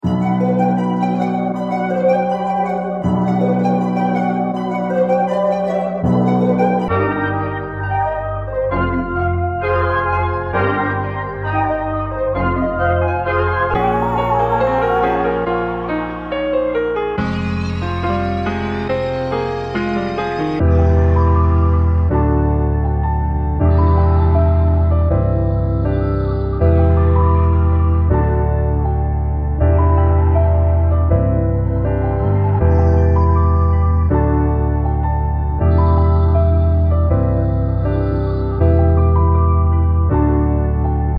这个样本包是和环境陷阱样本包，灵感来自仙人掌杰克，特拉维斯斯科特，唐托利弗和更多人的声音。